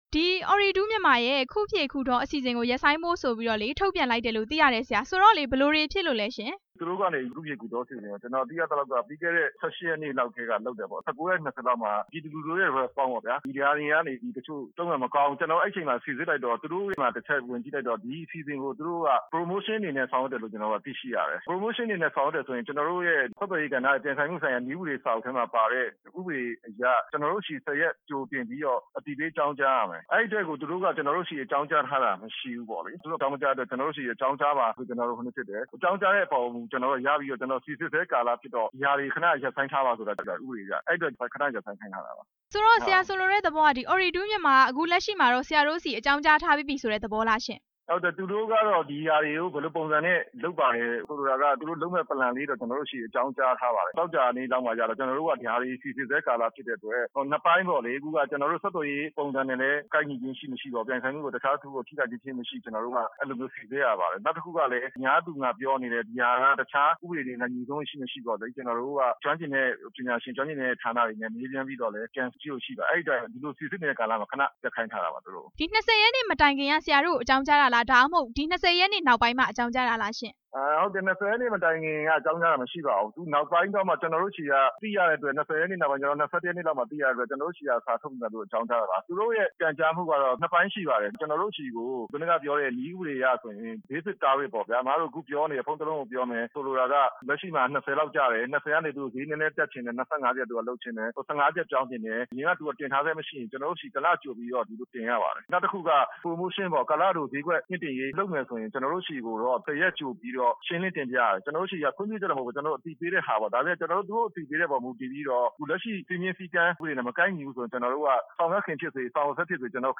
ဆက်သွယ်ရေးညွှန်ကြားမှုဦးစီးဌာန ဒုတိယညွှန်ကြားရေးမှူးချုပ် ဦးမျိုးဆွေကို RFA သတင်းထောက်